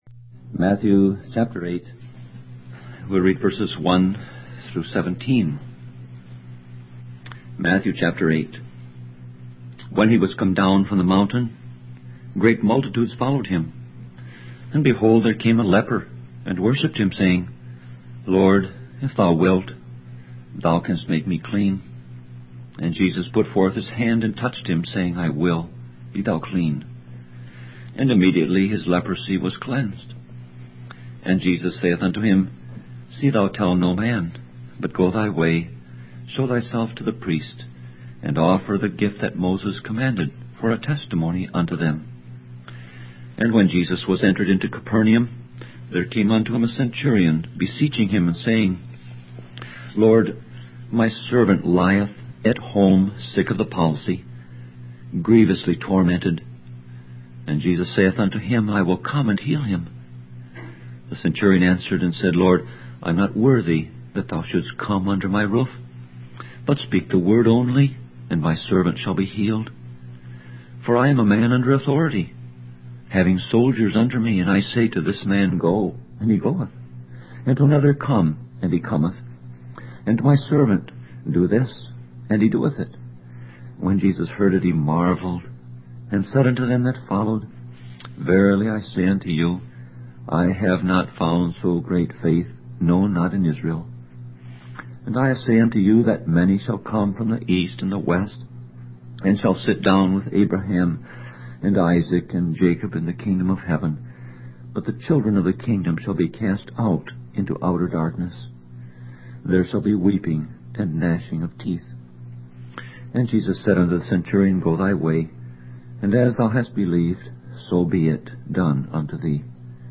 Sermon Audio Passage: Matthew 8:1-17 Service Type